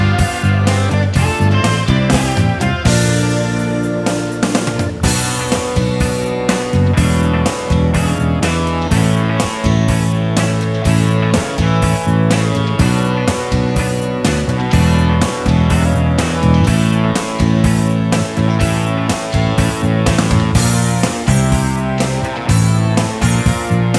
no Backing Vocals Soft Rock 5:09 Buy £1.50